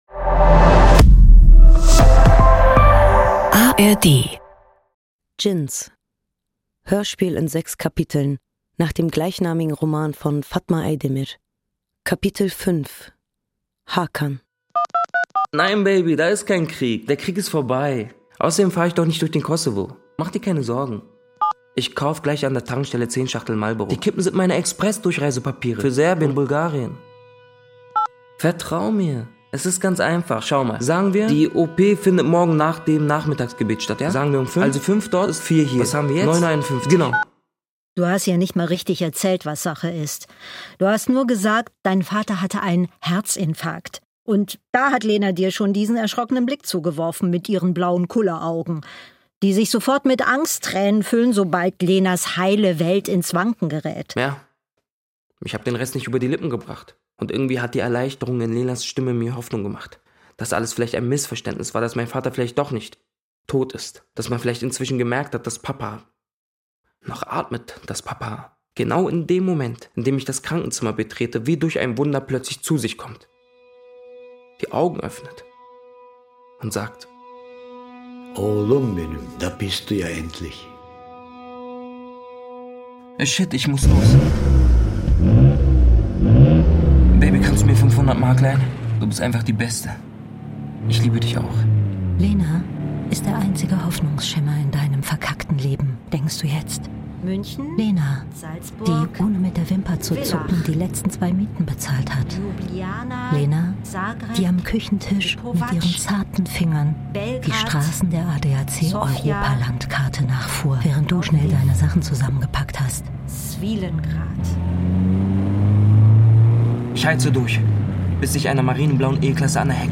Produktion: NDR 2024.